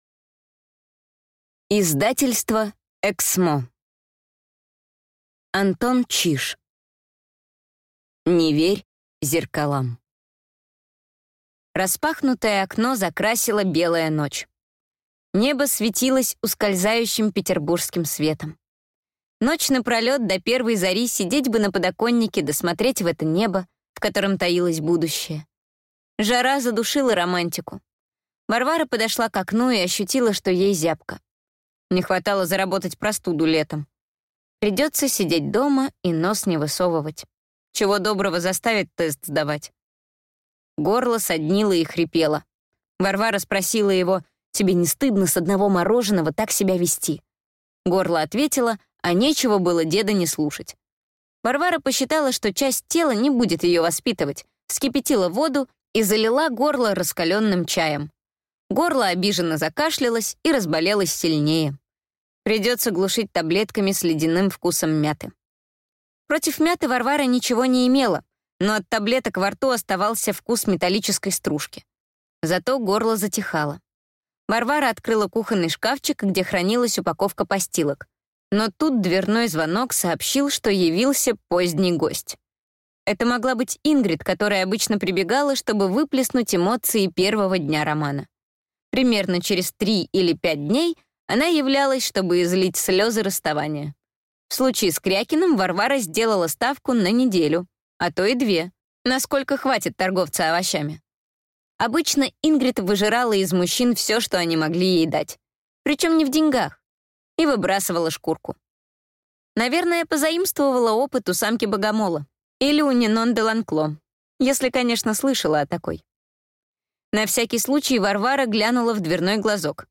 Аудиокнига Не верь зеркалам | Библиотека аудиокниг